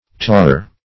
tawer - definition of tawer - synonyms, pronunciation, spelling from Free Dictionary Search Result for " tawer" : The Collaborative International Dictionary of English v.0.48: Tawer \Taw"er\, n. One who taws; a dresser of white leather.